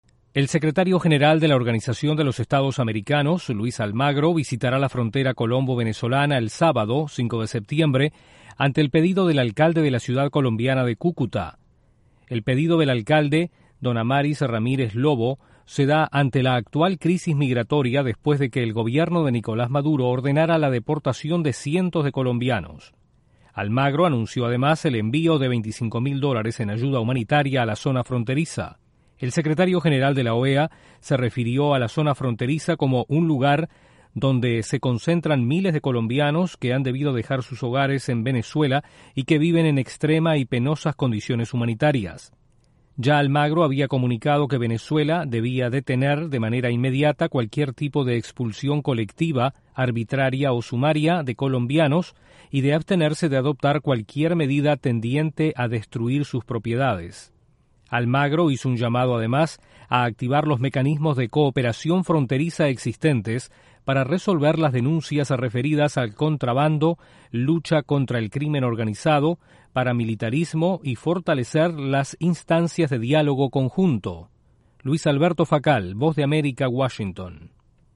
El secretario general de la OEA visitará el fin de semana la frontera entre Colombia y Venezuela. Desde la Voz de América en Washington